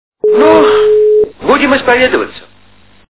» Звуки » Люди фразы » Ну... - Будем исповедоваться?
При прослушивании Ну... - Будем исповедоваться? качество понижено и присутствуют гудки.